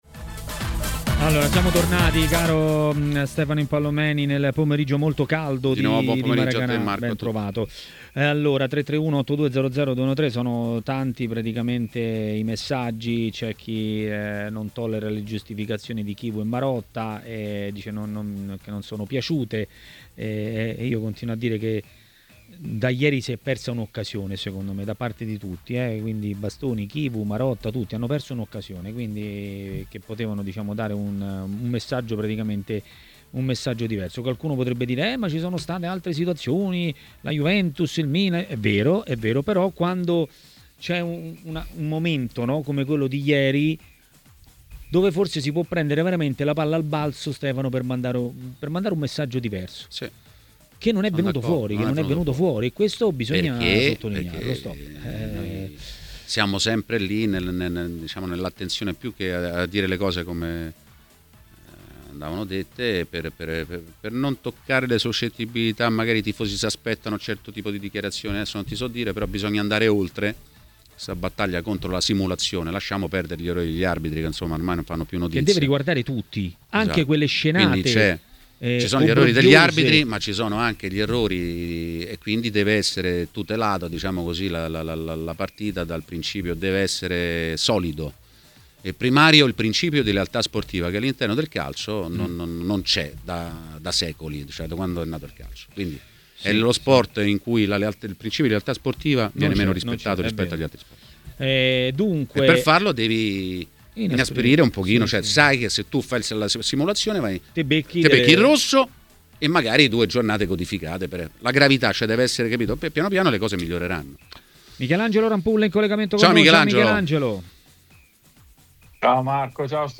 L'ex calciatore Massimo Orlando ha parlato a TMW Radio, durante Maracanà.